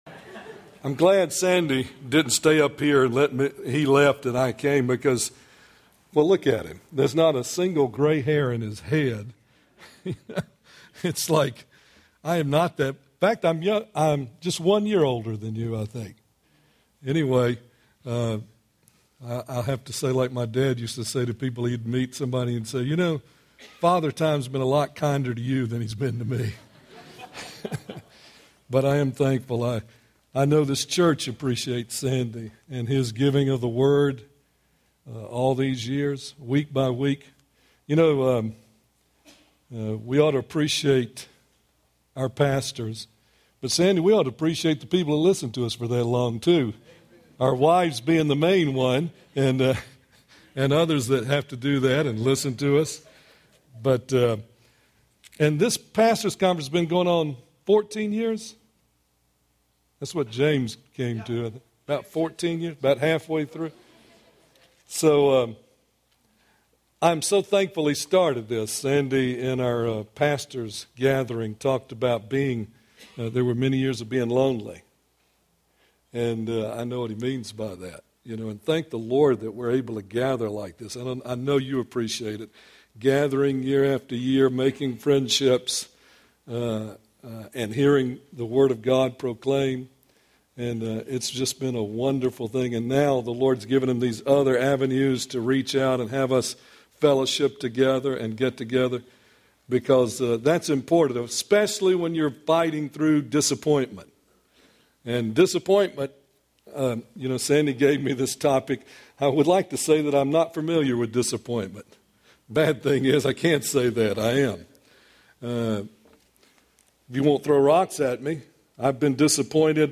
2010 Home » Sermons » Session 9 Share Facebook Twitter LinkedIn Email Topics